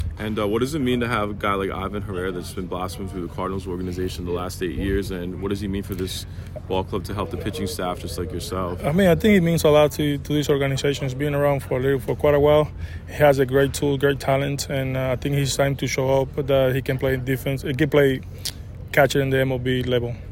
Contreras spoke before the Cardinals’ spring training game against the New York Mets at Roger Dean Chevrolet Stadium.
Wilson-Contreras-Interview_On-Cardinals-Catcher-Herrera.mp3